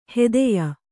♪ hedeya